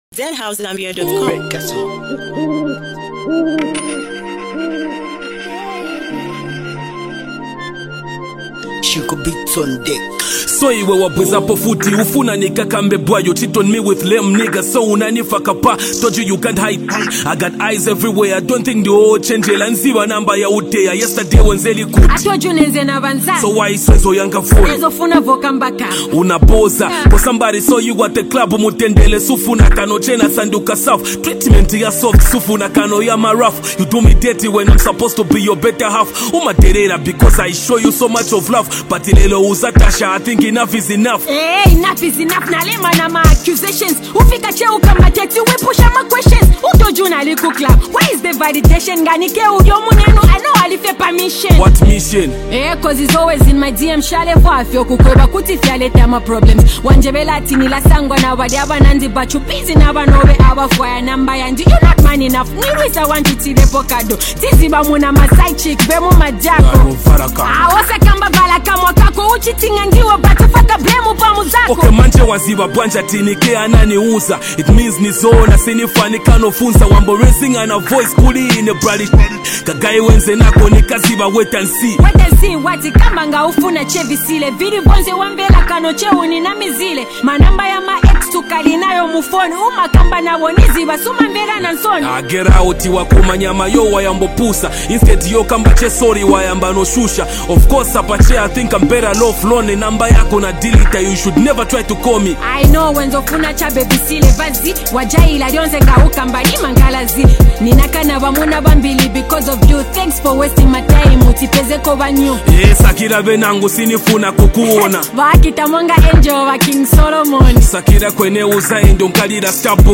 exchanging emotional lines about love and heartbreak